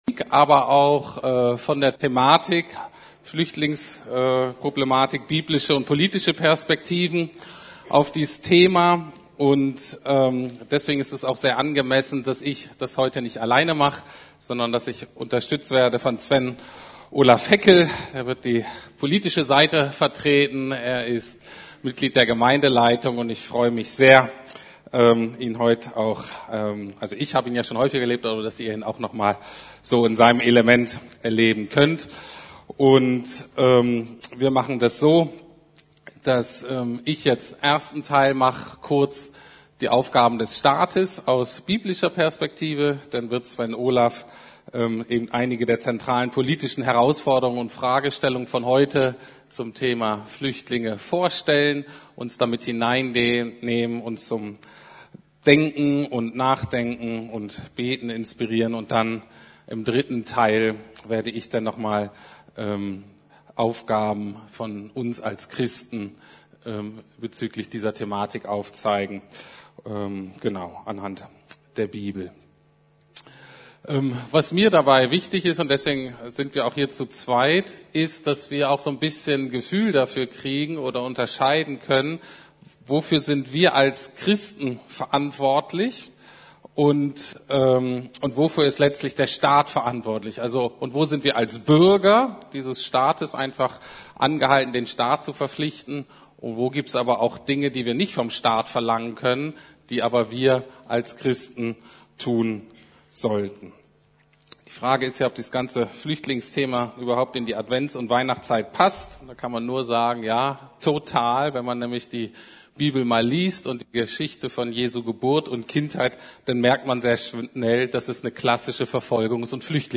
Biblische und politische Perspektiven zur Flüchtlingsproblematik ~ Predigten der LUKAS GEMEINDE Podcast